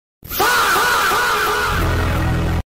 Fahhhh Bass Boosted - Botão de Efeito Sonoro